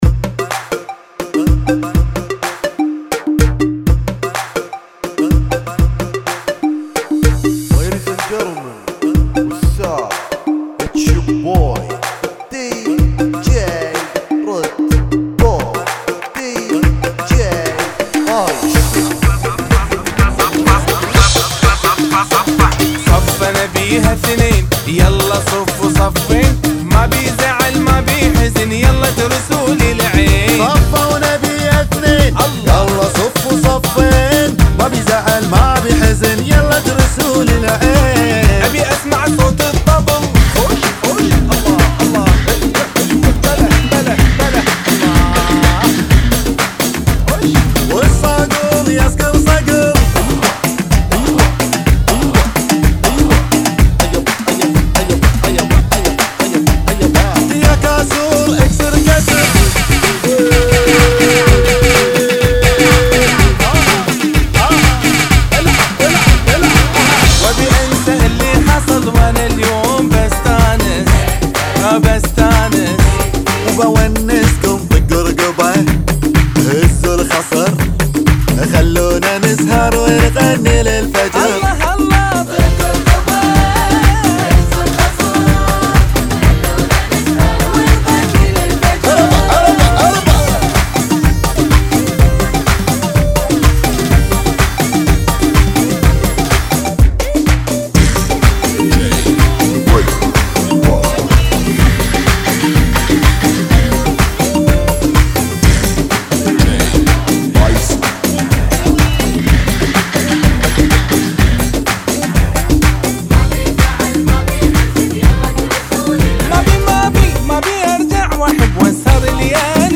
125 BPM